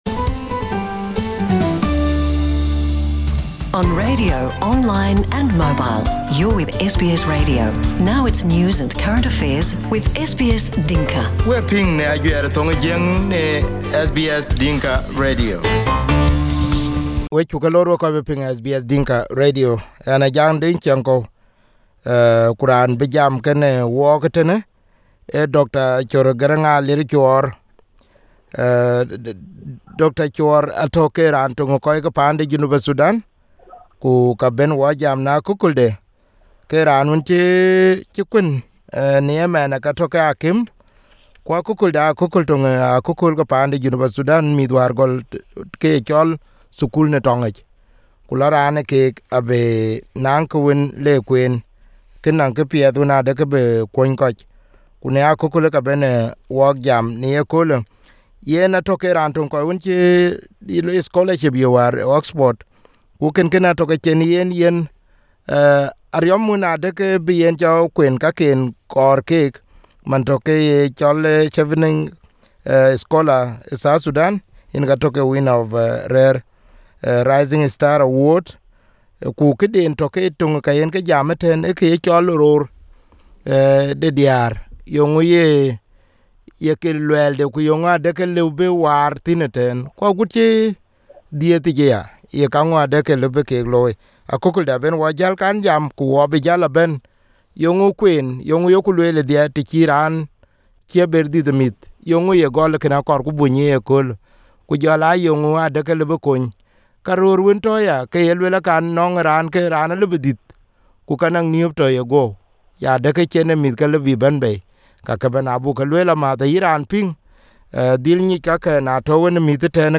This is the part one of his first interview on SBS Dinka and the rest of parts will focus on women and men health.